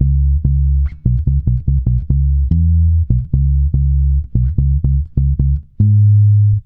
-MM RAGGA C#.wav